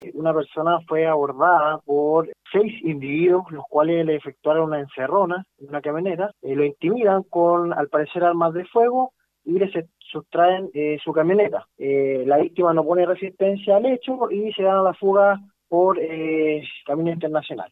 cu-persecucion-carabineros-.mp3